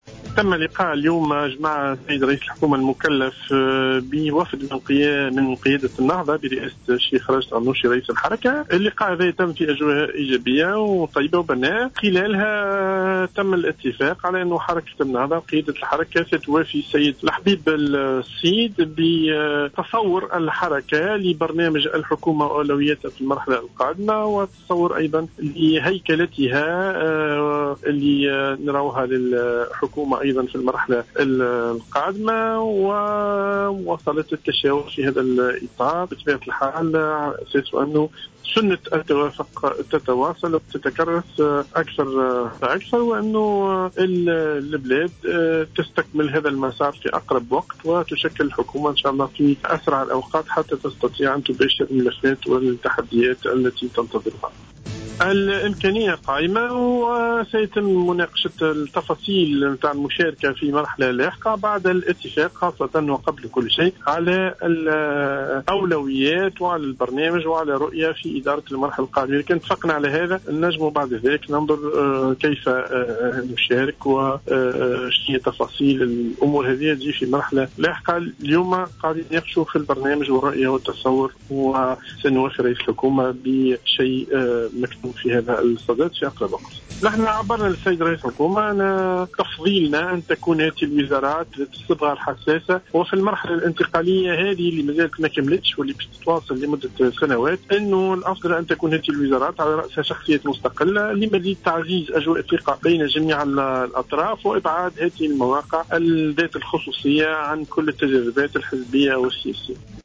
أكد الناطق الرسمي بإسم حركة النهضة زياد العذاري في تصريح للجوهرة أف أم اليوم...